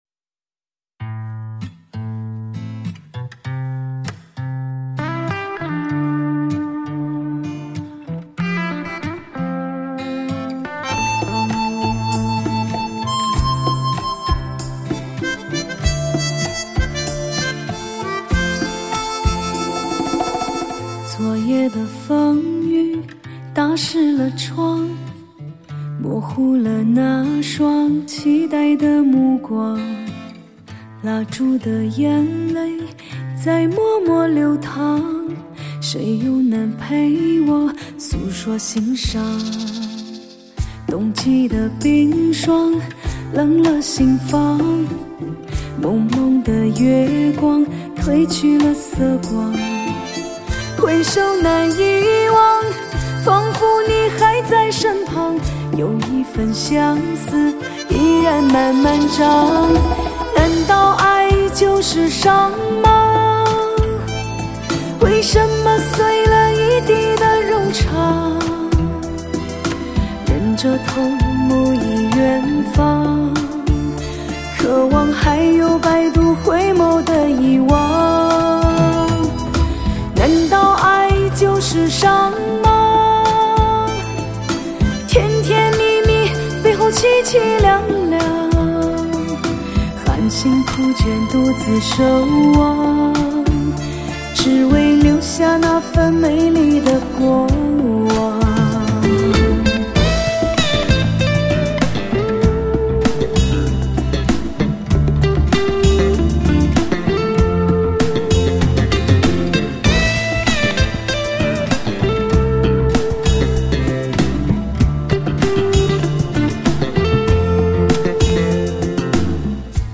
华语流行
委婉的旋律，质感的声音，情感的刻骨，句句刺入心扉……